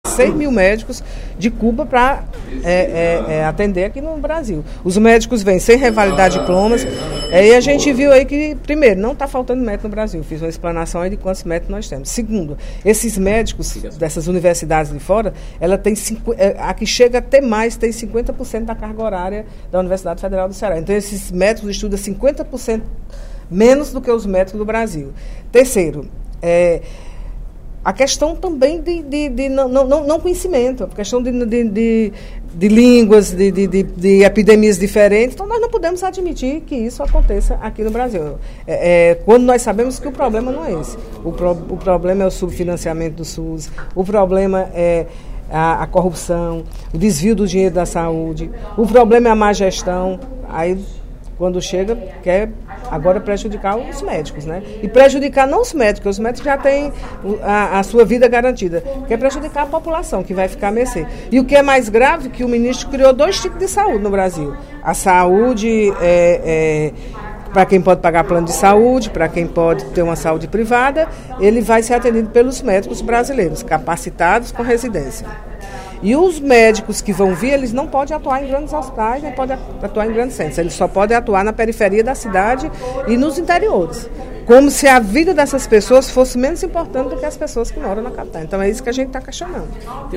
A contratação pelo governo federal de seis mil médicos cubanos para atuar no Brasil, sem o exame de revalidação do diploma, foi o tema do pronunciamento do primeiro expediente da sessão plenária desta sexta-feira (14/06), da deputada Mirian Sobreira (PSB).